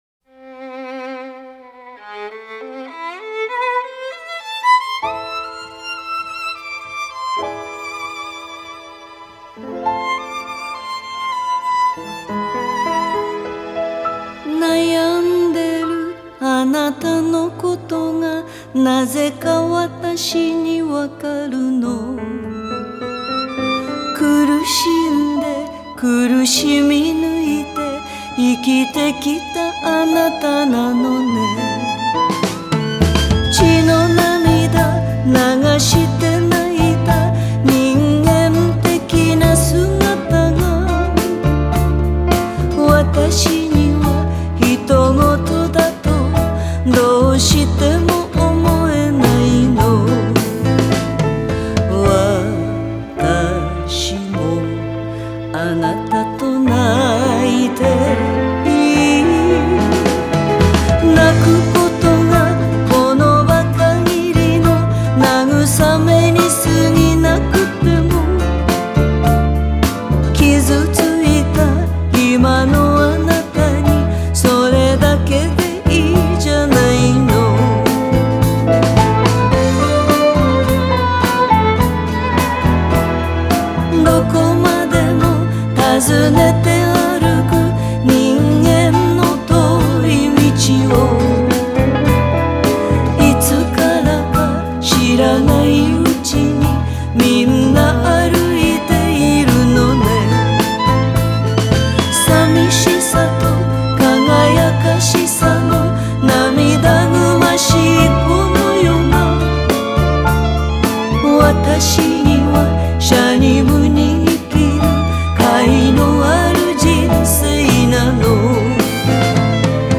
ジャンル: Jazz